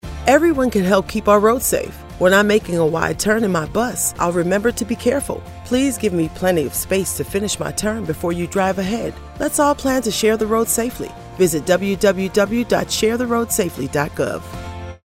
Audio Public Service Announcements (PSAs)